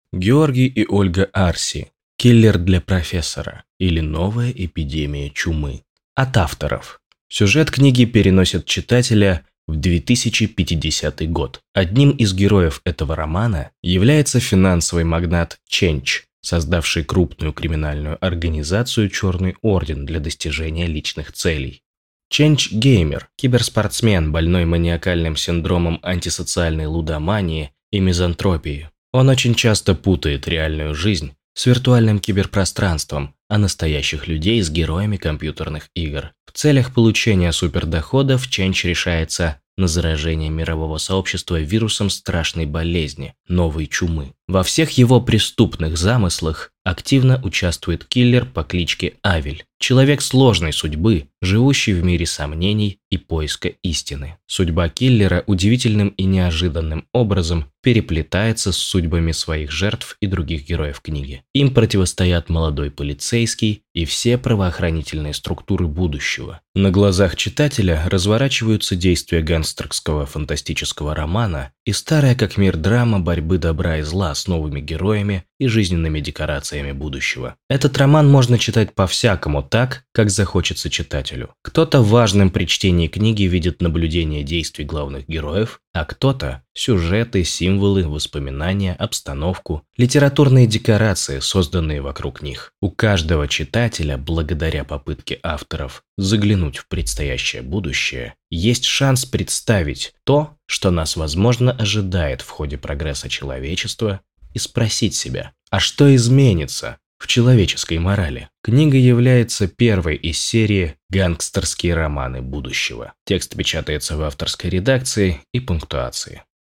Муж, Аудиокнига/Средний